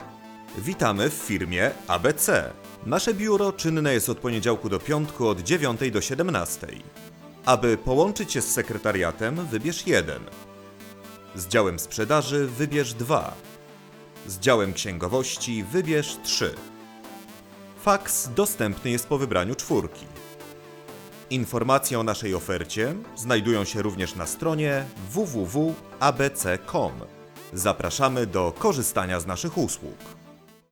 Male 30-50 lat
Narracja lektorska
Zapowiedź telefoniczna